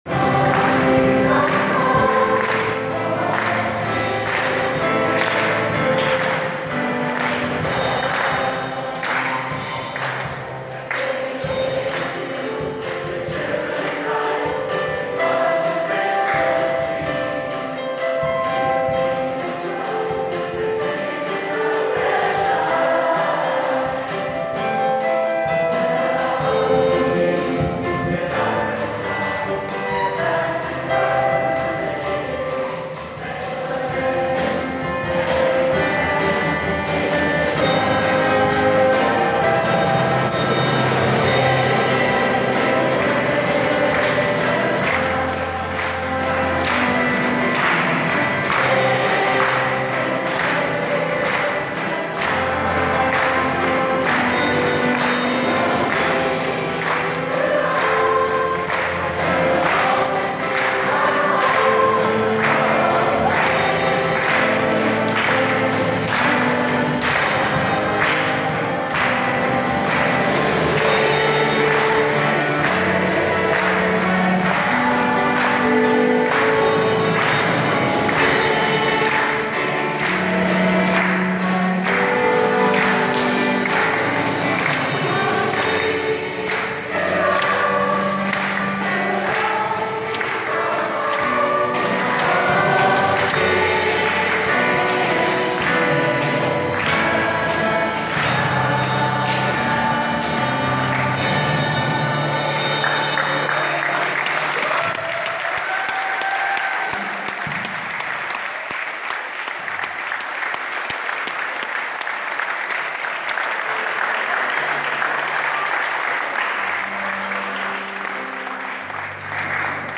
Weihnachtskonzerte